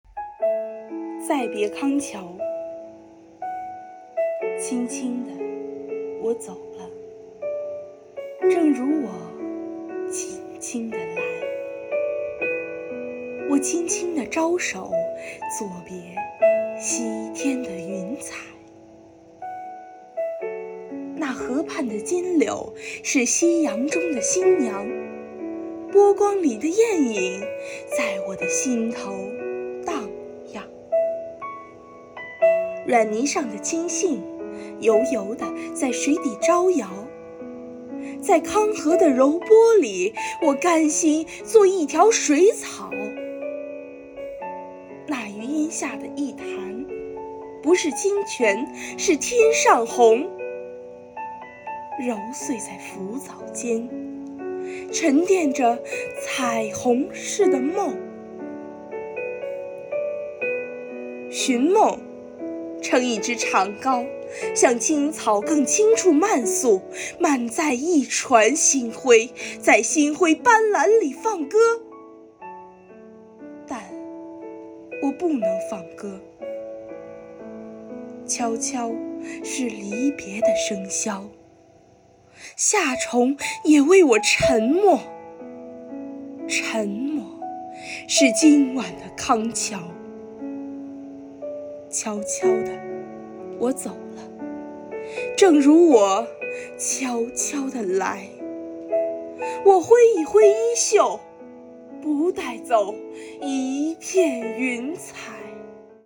诵中华经典，品古韵流芳----中华经典诵读大赛圆满落幕